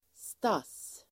Ladda ner uttalet
stass substantiv (vardagligt), finery [informal] Uttal: [stas:] Böjningar: stassen Synonymer: kläder, utstyrsel Definition: festkläder Exempel: de kom i bästa söndagsstassen (they came in their best finery)